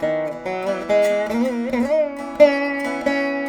137  VEENA.wav